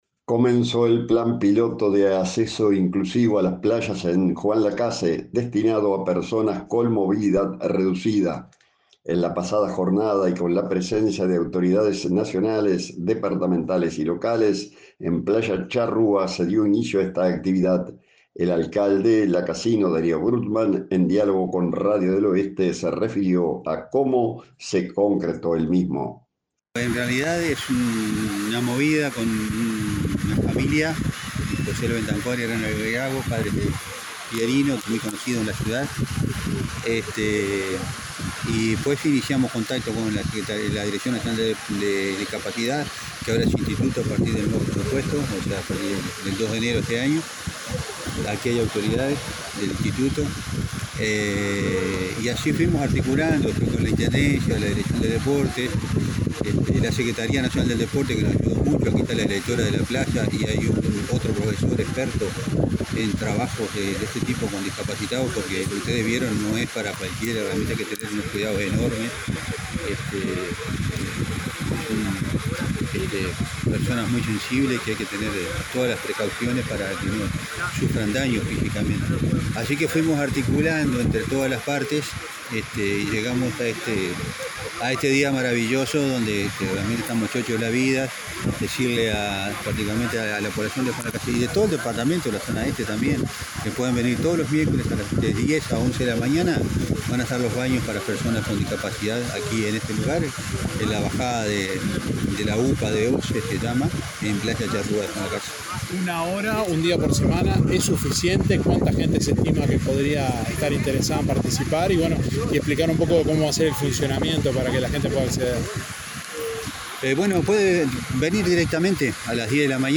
En la pasada jornada y con la presencia de autoridades nacionales, departamentales y locales en Playa Charrúa se dio inicio a esta actividad, en diálogo con Radio del Oeste el alcalde Darío Brugman se refirió a como se concretó el mismo.